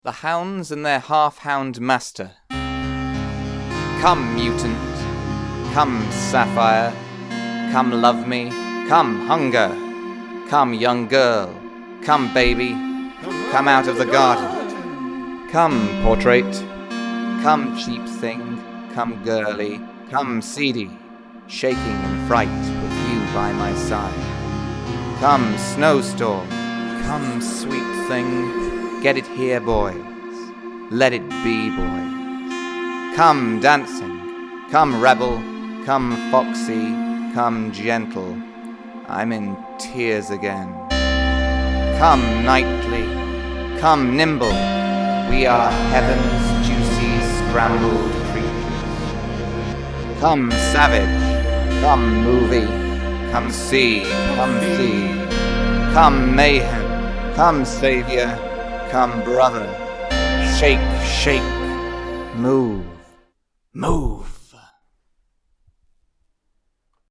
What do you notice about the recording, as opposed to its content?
I’ve recorded a version of the poem in a style that somewhat apes the opening track, ‘Future Legend’.